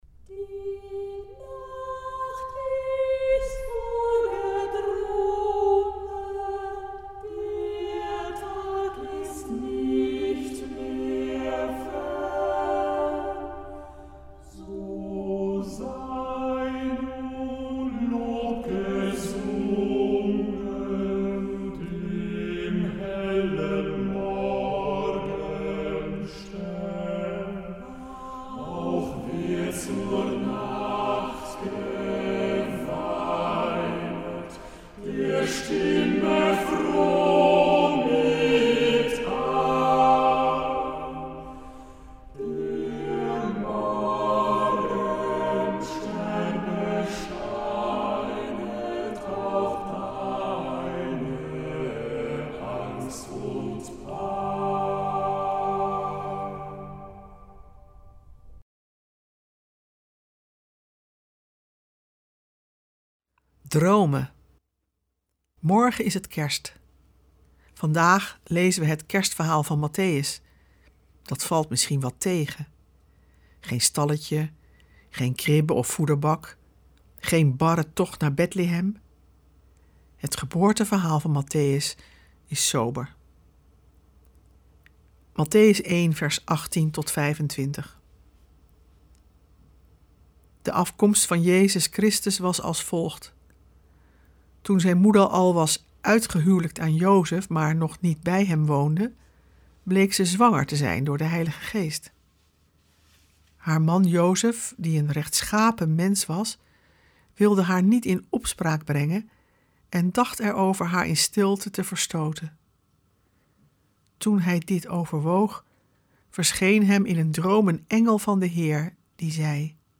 We verbinden ze met onze tijd en we luisteren naar prachtige muziek en poëzie.